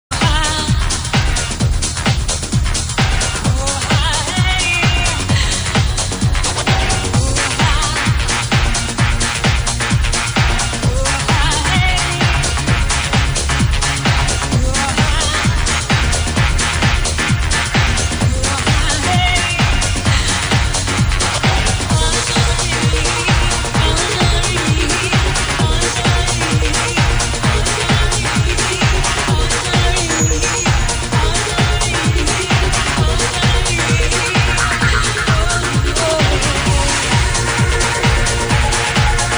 sounds like with Live vocals